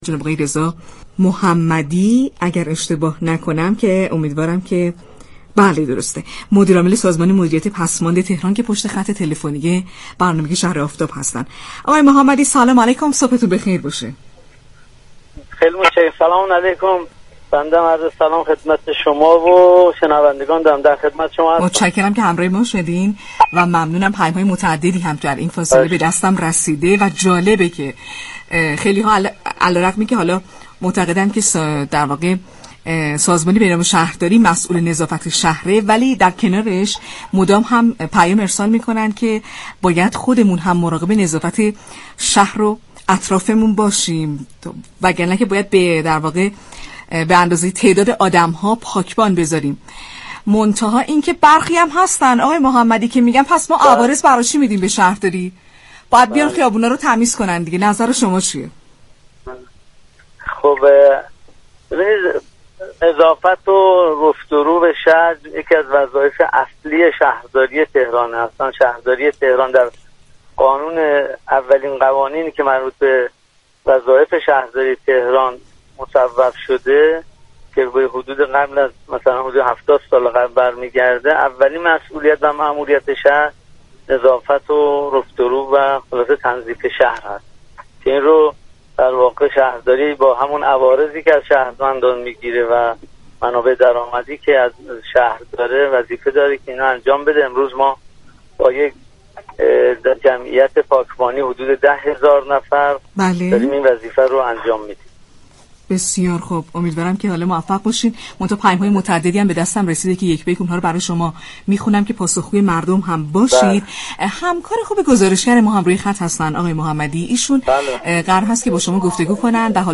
گفت و گو با «شهر آفتاب» رادیو تهران